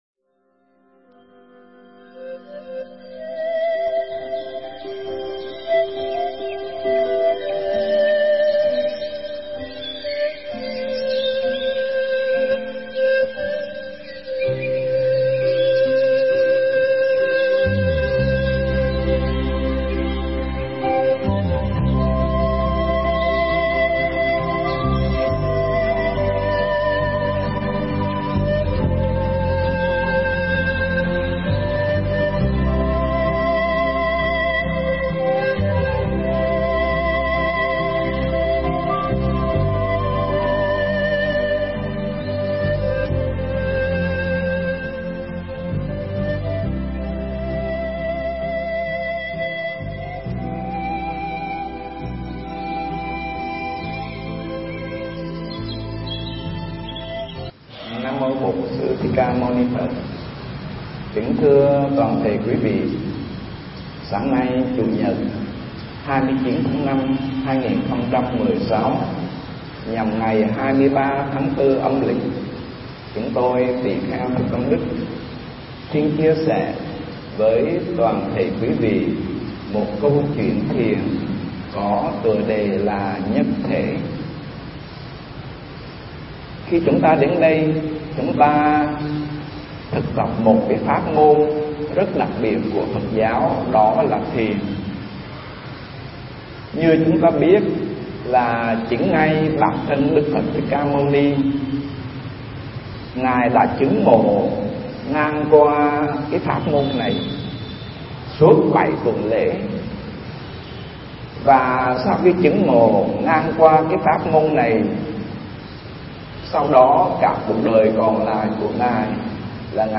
Mp3 thuyết pháp Nhất Thể